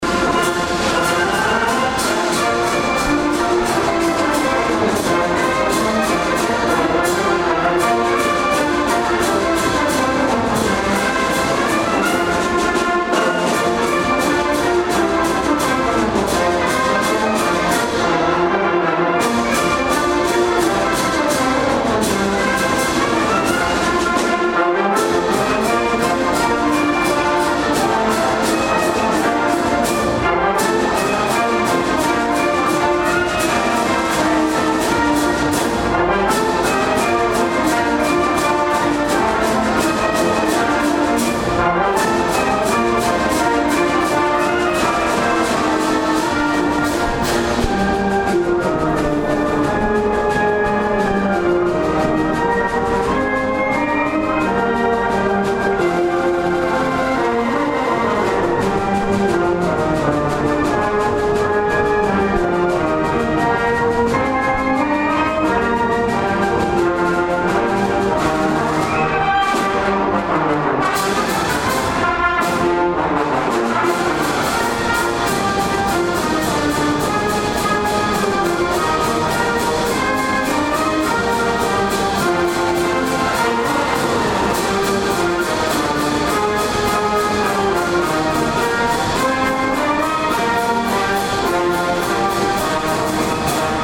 Galop